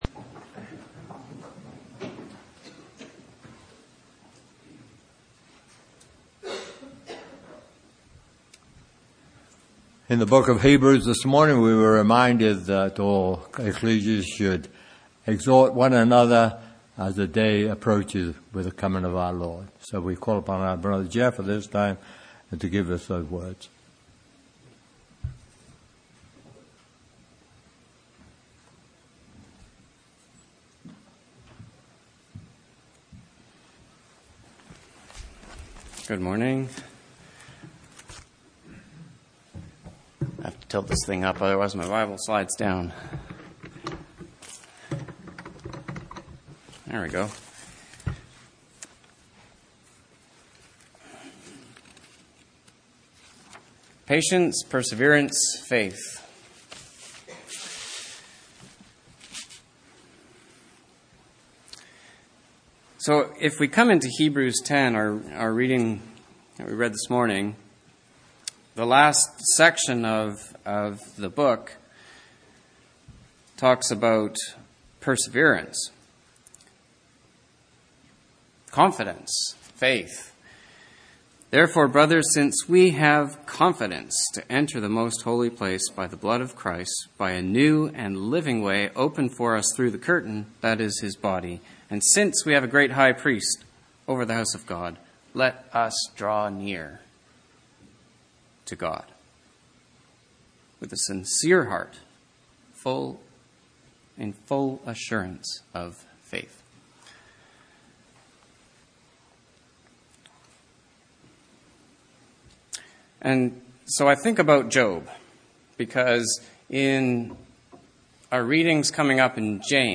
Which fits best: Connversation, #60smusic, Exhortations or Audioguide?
Exhortations